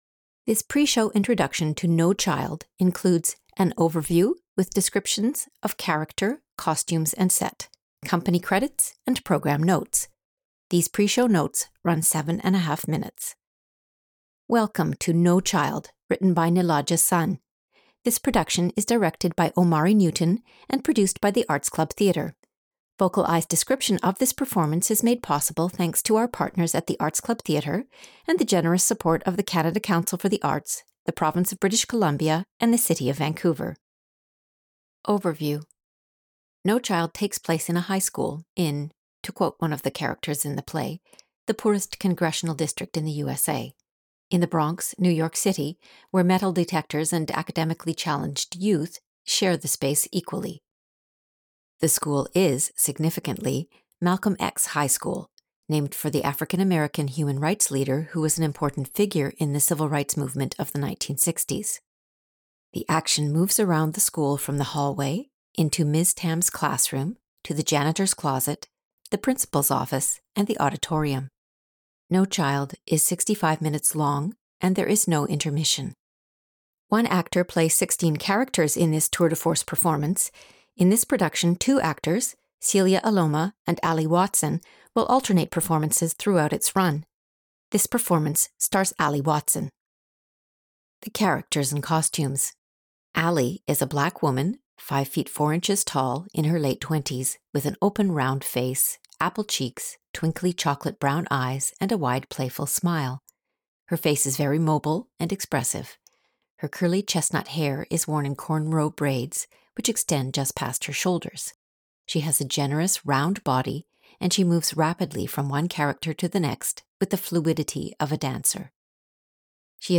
No Child… Pre-show Notes (audio):
No-Child-Pre-show-introduction-ArtsClubVocalEye.mp3